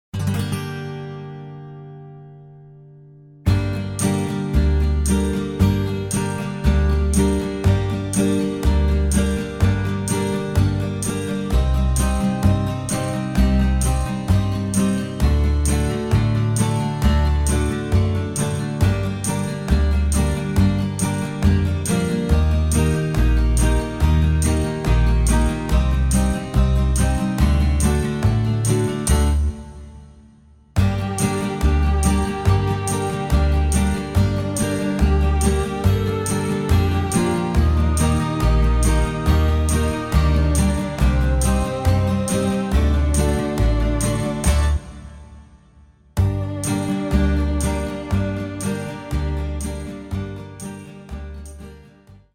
cantu corsu